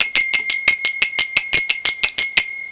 Serie ininterrumpida de 15 choques de crótalos, alternando ambas manos.
takaTaka takaTaka takaTaka takaDUM //